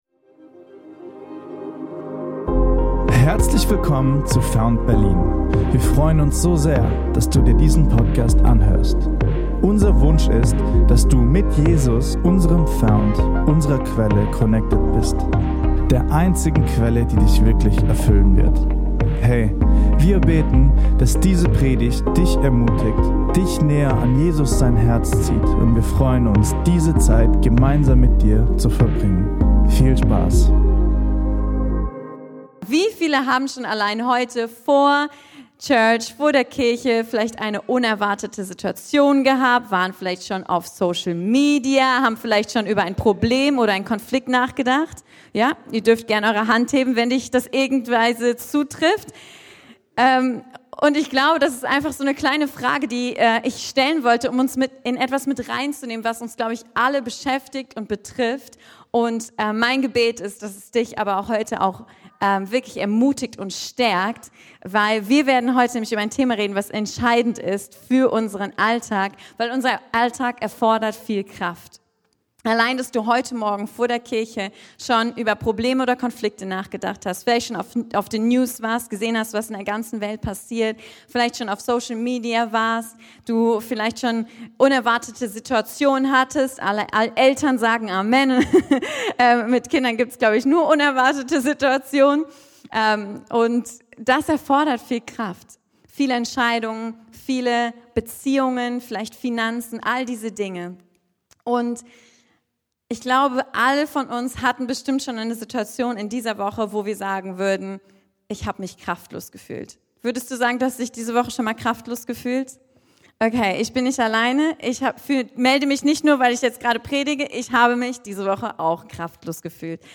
Fühlst du dich in deinem Alltag kraftlos, überfordert und hast das Gefühl keine Ruhe zu bekommen? In dieser Predigt erfährst Du, wie du an einen Ort von wahrer Ruhe kommen kannst und so neue Kraft für dein Leben schöpfen wirst.